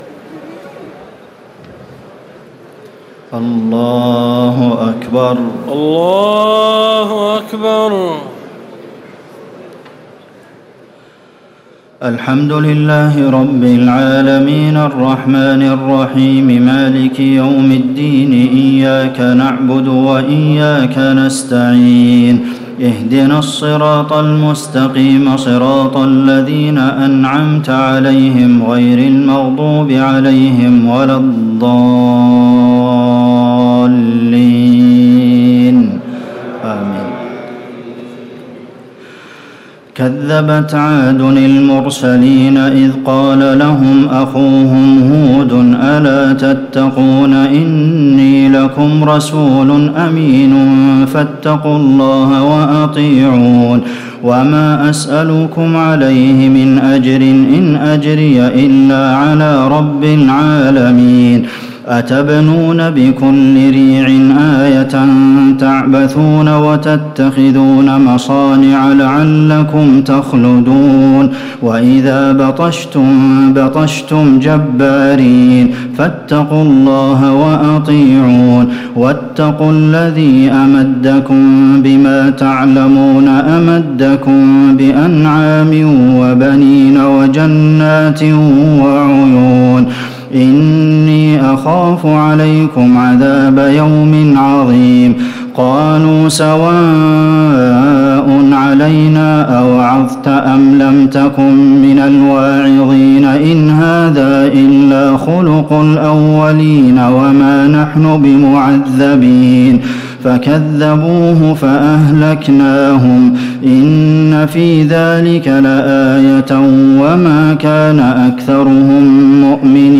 تراويح الليلة الثامنة عشر رمضان 1439هـ من سورتي الشعراء (123-227) والنمل (1-53) Taraweeh 18 st night Ramadan 1439H from Surah Ash-Shu'araa and An-Naml > تراويح الحرم النبوي عام 1439 🕌 > التراويح - تلاوات الحرمين